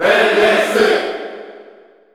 Crowd cheers (SSBU) You cannot overwrite this file.
Byleth_Female_Cheer_Korean_SSBU.ogg